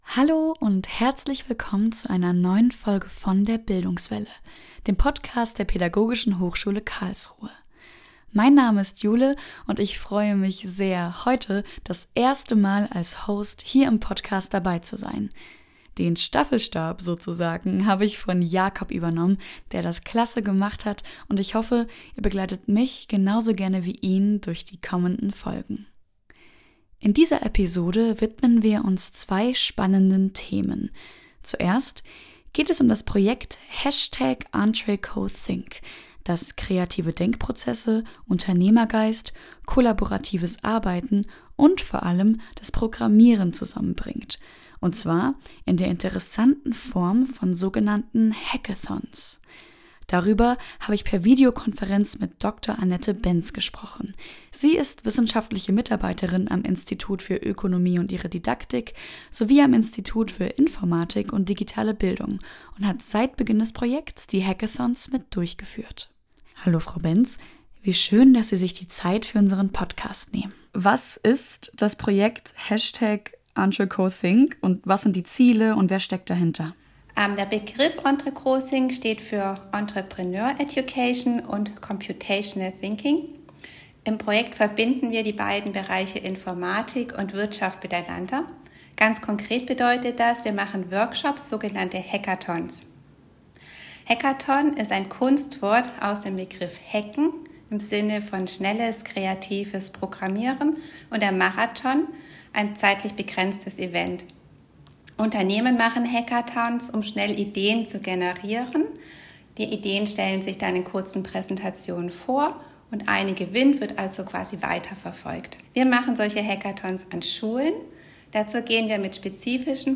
Es geht darum, wie den neuen Studierenden ein guter Start ermöglicht wird und wie ihre Zeit an der PHKA aussehen wird. Doch nicht nur das - auch hören wir von drei Austauschstudenten selbst, wie sie sich in Karlsruhe eingelebt haben.